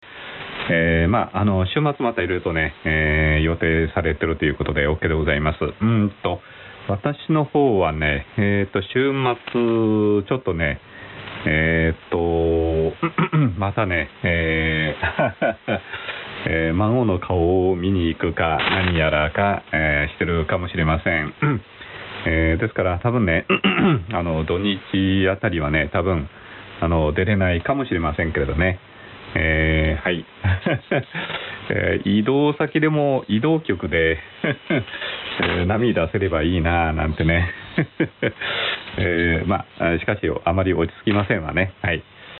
Sample Hi‑Fi SSB Audio
Rx:FT DX 9000D with Improved AF amp. / Tx: PSN + TS-850 , Rx band width 4kHz